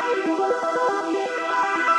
SaS_MovingPad03_120-E.wav